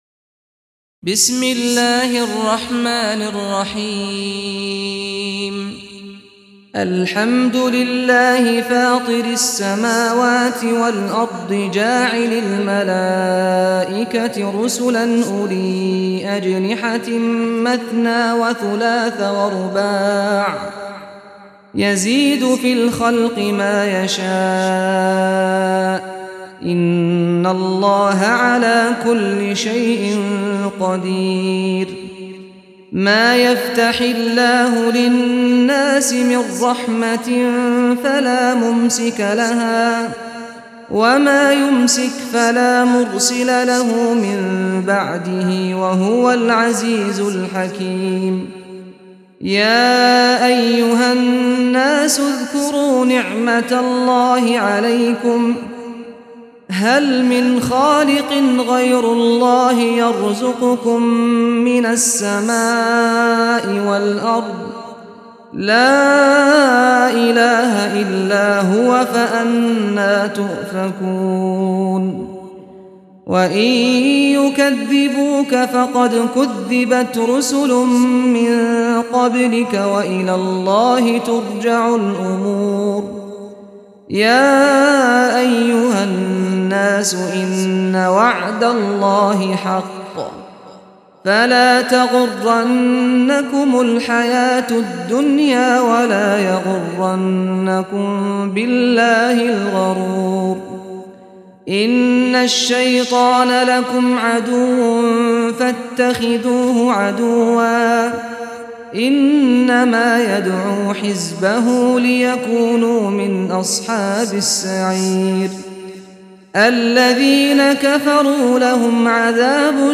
سورة فاطر - سعد بن سعيد الغامدي (صوت - جودة فائقة. التصنيف: تلاوات مرتلة